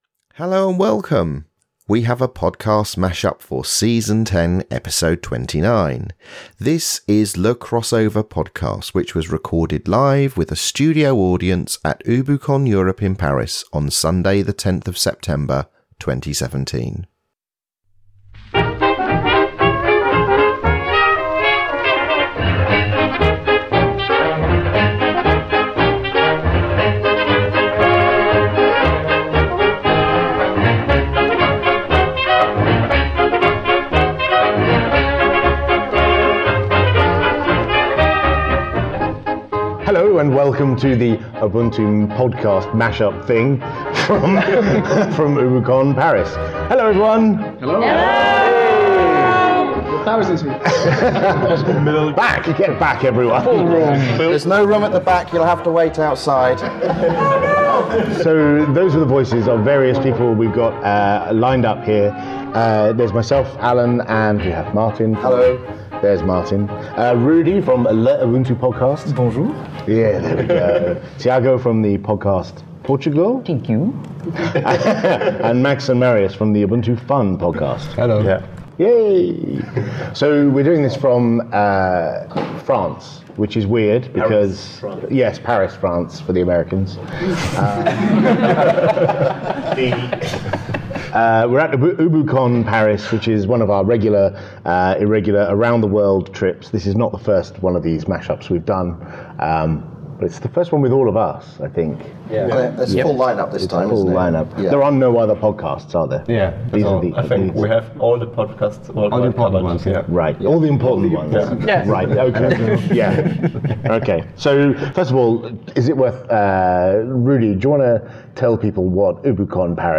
This is Le Crossover Ubuntu Mashup Podcast thingy recorded live at UbuCon Europe in Paris, France.